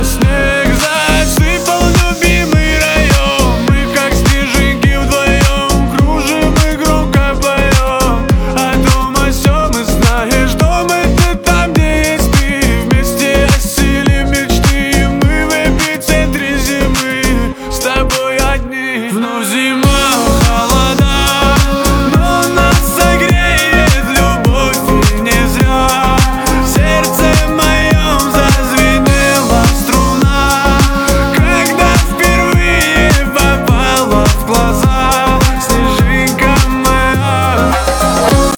Жанр: Русские песни